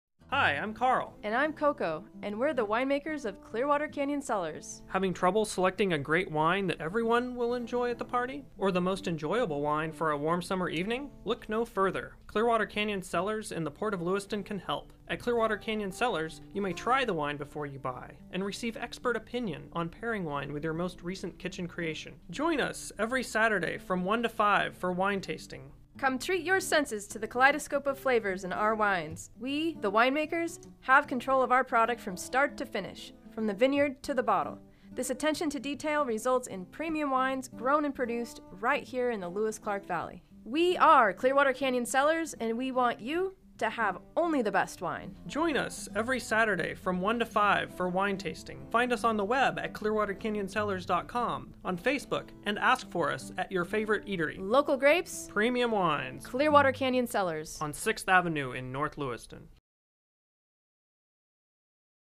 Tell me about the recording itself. Our first radio ad! This ad will be playing on pacific empire radio’s 101.5 atation broadcasting to the Lewis Clark Valley and surrounding area.